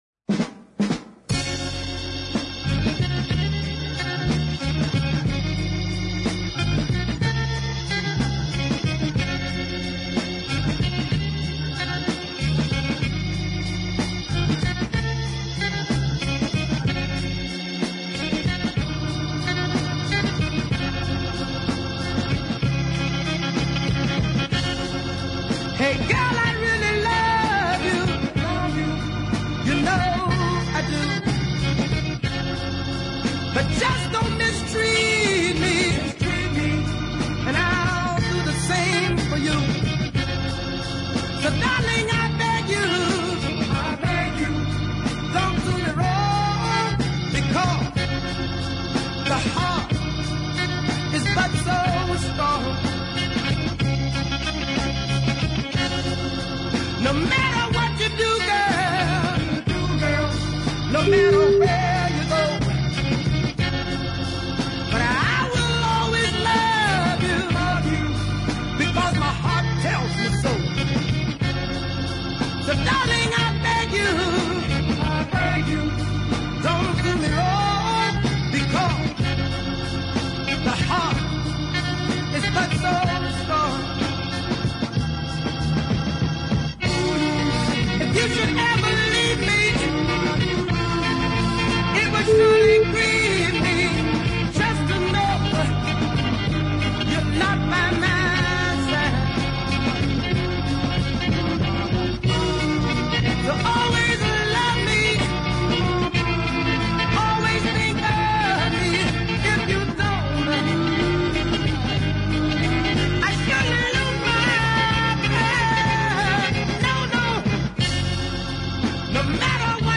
super gritty voice
deep ballad